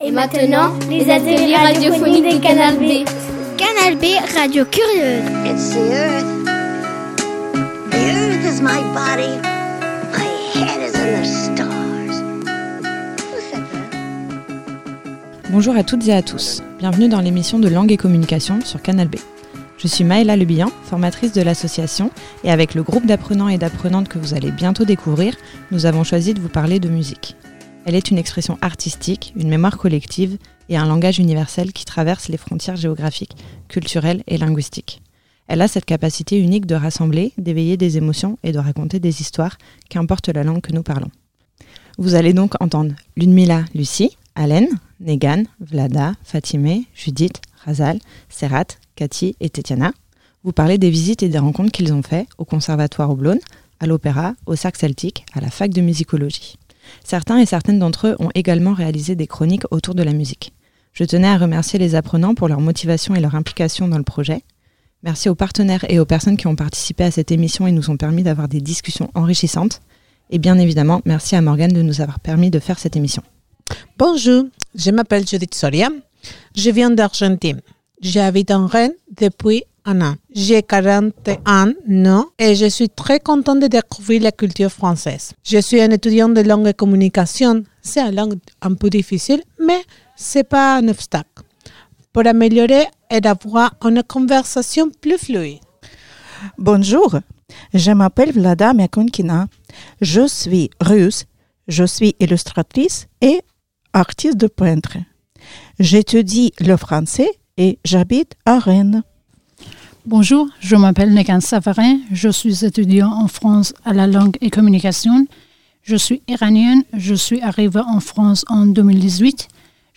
Cette émission a été réalisée en partenariat avec l'association Langue et Communication, organisme de formation spécialisé dans l'enseignement du français aux personnes étrangères et d'origine étrangère.